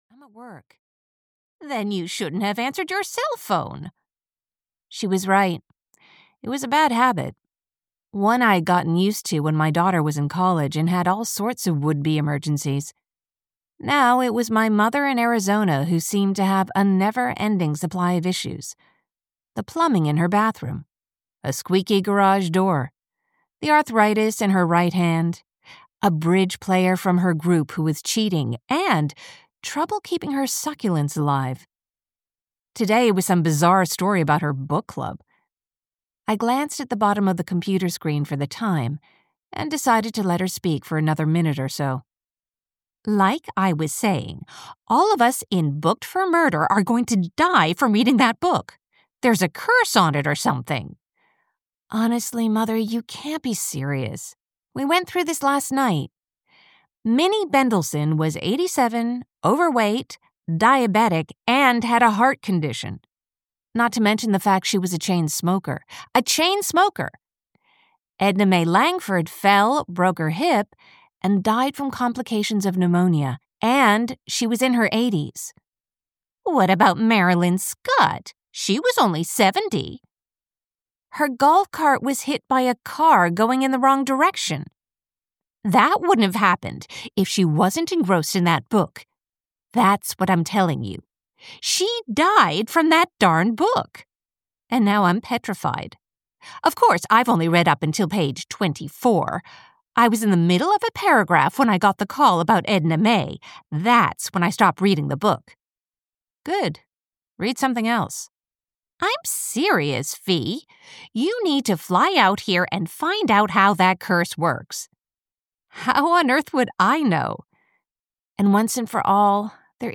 Booked for Murder (EN) audiokniha
Ukázka z knihy